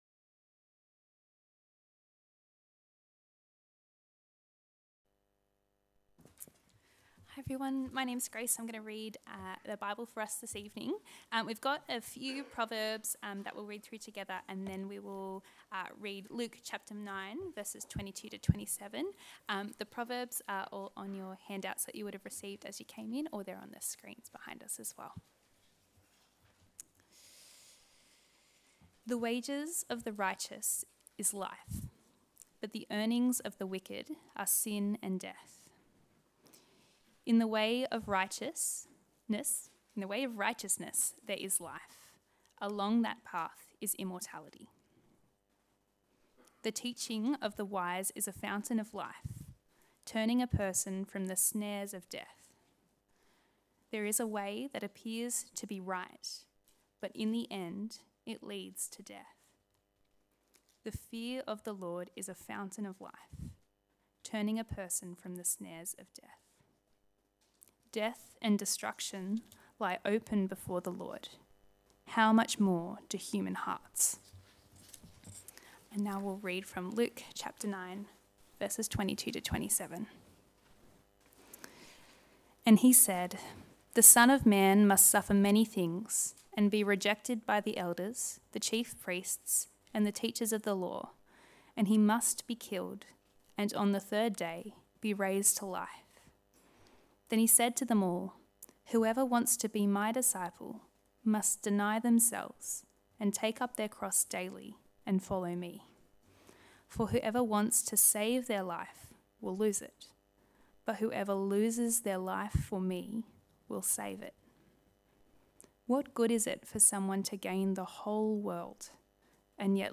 Sermon: Life & Death